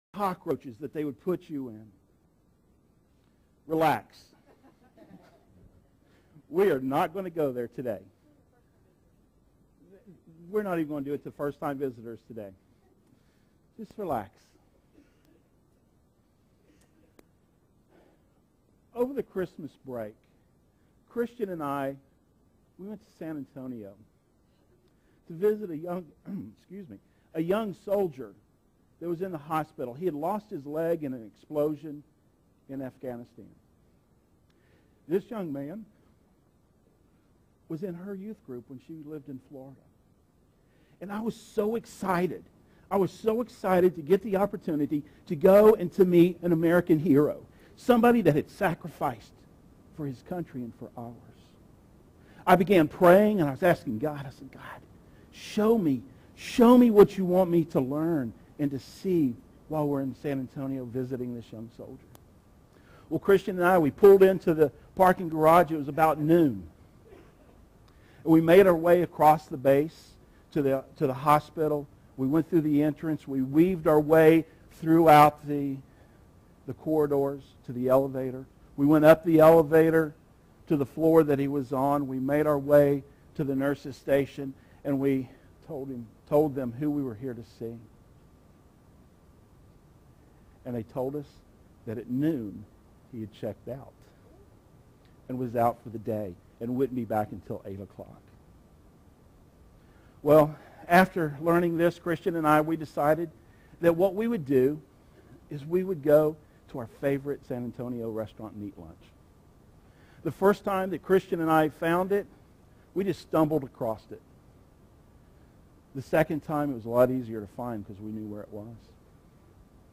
Two Rivers Bible Church - Sermons